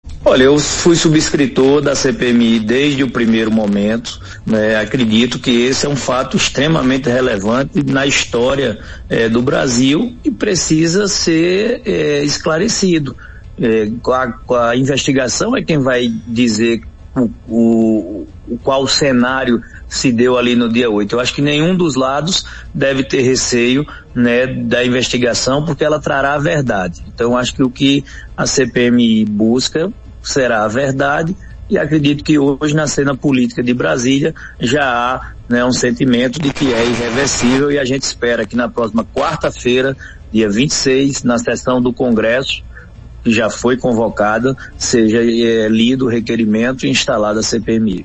As declarações repercutiram na Arapuan FM.